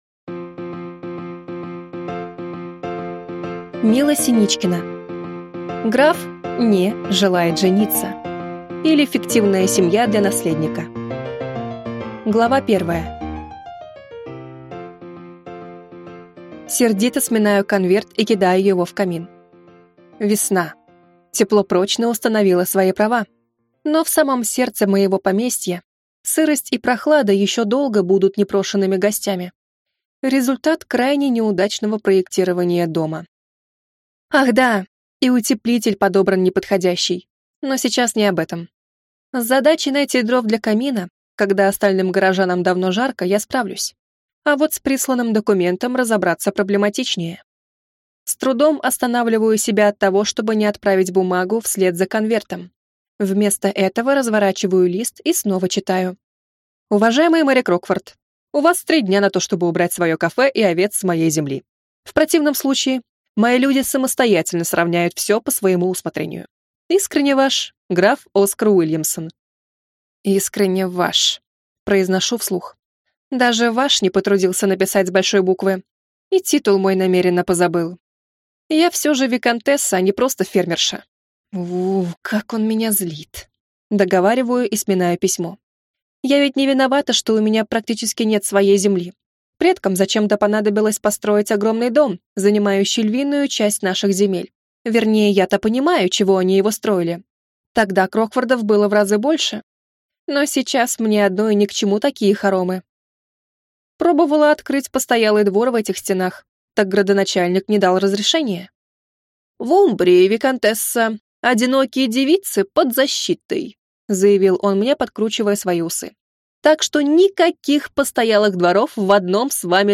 Аудиокнига Граф (не) желает жениться или Фиктивная семья для наследника | Библиотека аудиокниг